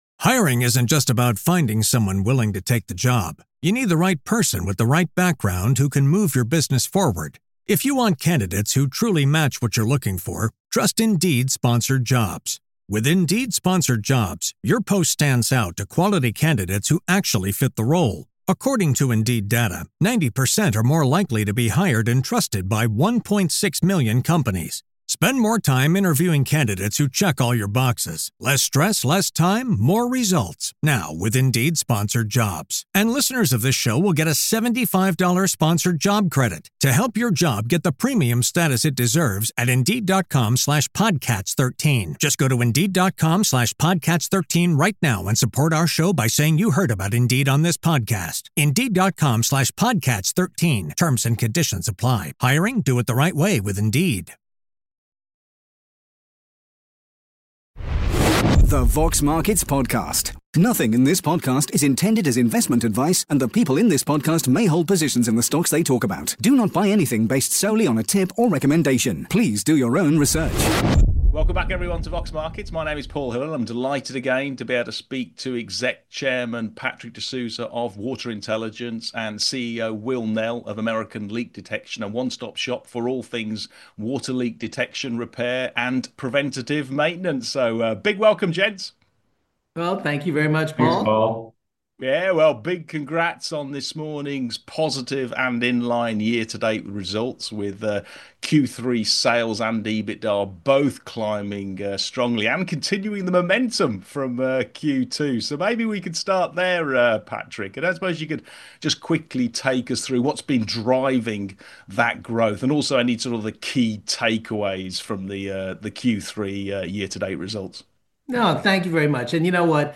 The Vox Markets Podcast / Interview